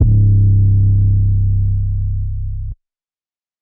808 Goose.wav